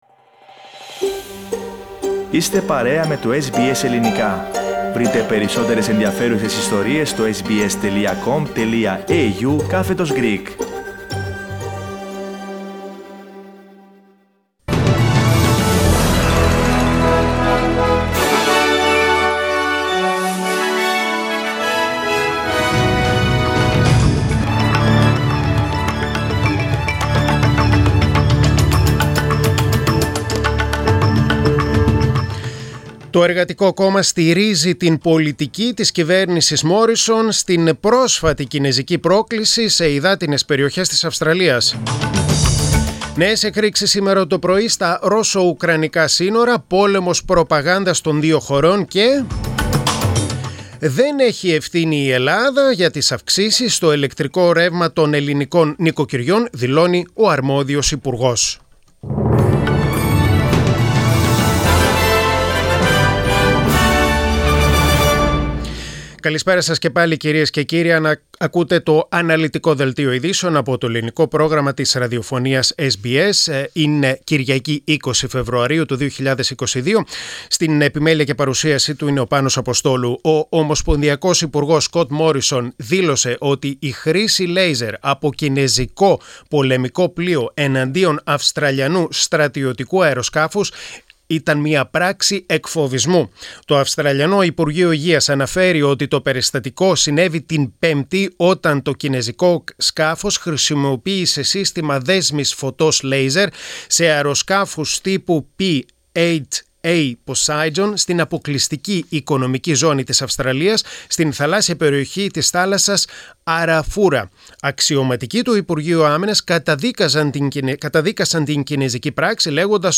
Greek main bulletin.